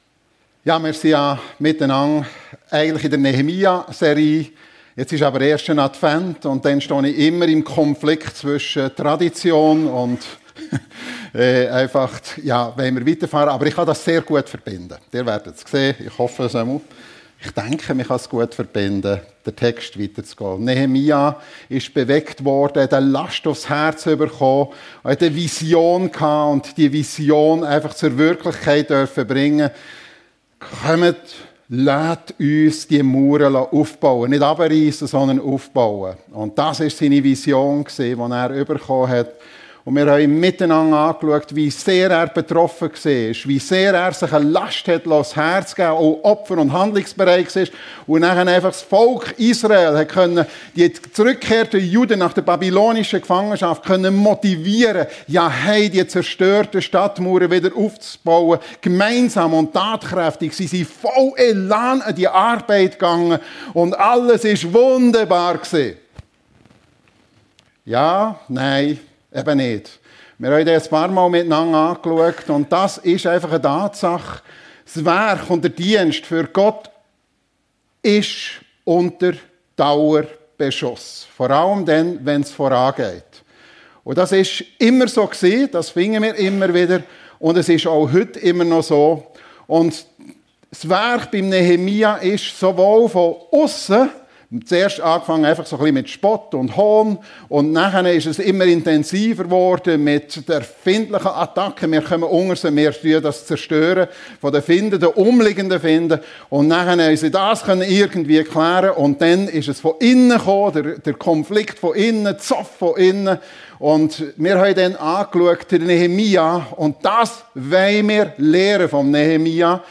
Nehemia - Geistliche Erweckung - ergriffen von Gott (Teil 6) ~ FEG Sumiswald - Predigten Podcast